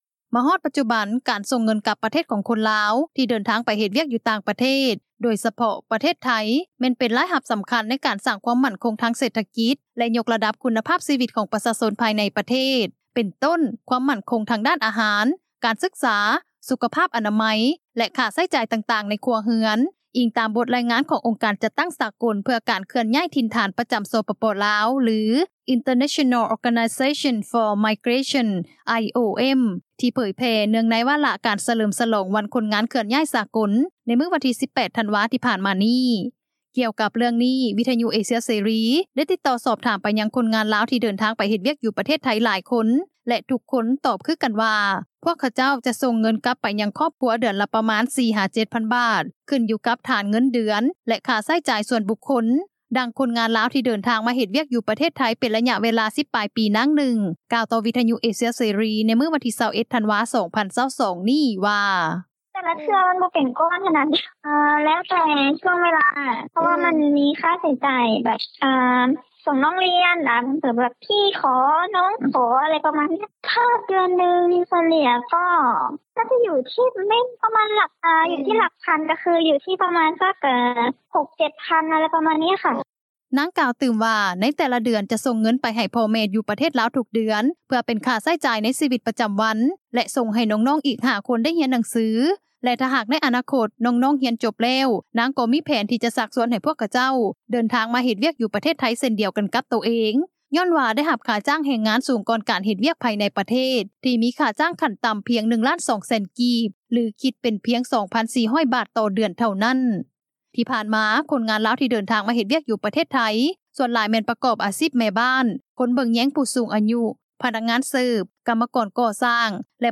ດັ່ງຄົນງານລາວ ທີ່ເດີນທາງມາເຮັດວຽກຢູ່ປະເທດໄທຍ ເປັນໄລຍະເວລາ 10 ປາຍປີ ນາງນຶ່ງ ກ່າວຕໍ່ວິທຍຸເອເຊັຽເສຣີ ໃນມື້ວັນທີ 21 ທັນວາ 2022 ນີ້ວ່າ:
ດັ່ງຄົນງານລາວ ທີ່ເຮັດວຽກເປັນກັມມະກອນກໍ່ສ້າງ ຢູ່ນະຄອນຫຼວງບາງກອກທ່ານນຶ່ງ ກ່າວຕໍ່ວິທຍຸເອເຊັຽເສຣີ ໃນມື້ດຽວກັນນີ້ວ່າ: